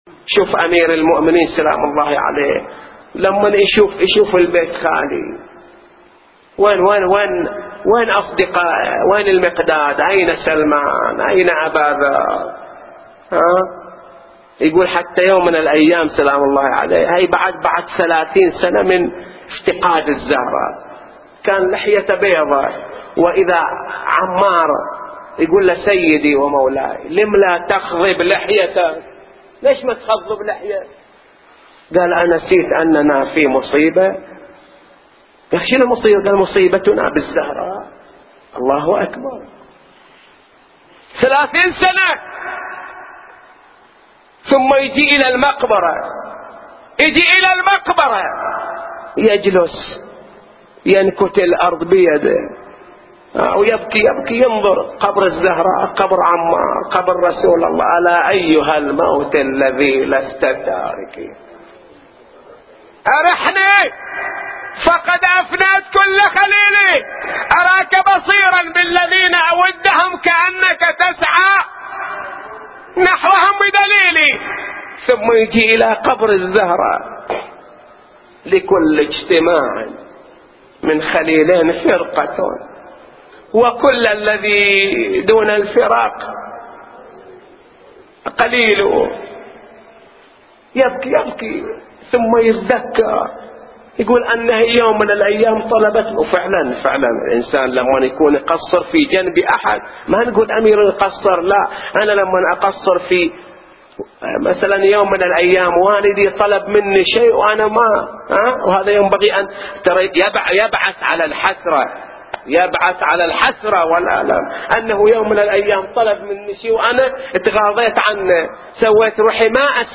نواعي وأبيات حسينية – 10